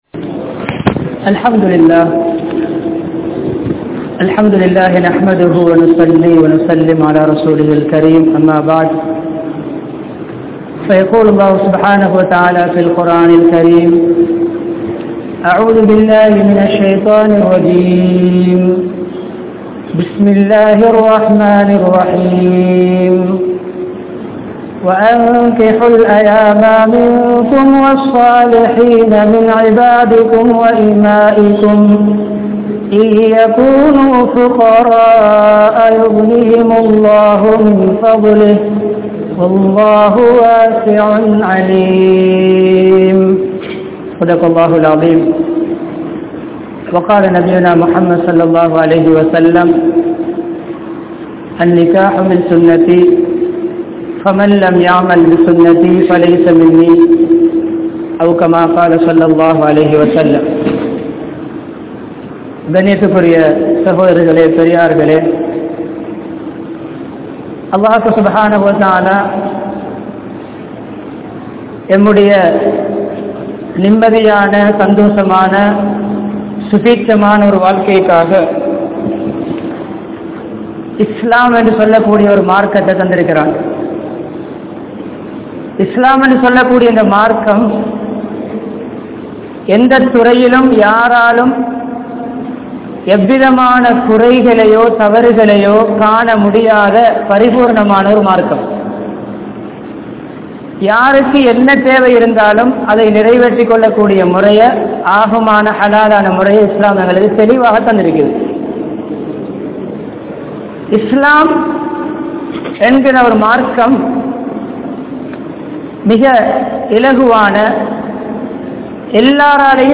Manaivien Ullaththai Veallugal (மனைவியின் உள்ளத்தை வெல்லுங்கள்) | Audio Bayans | All Ceylon Muslim Youth Community | Addalaichenai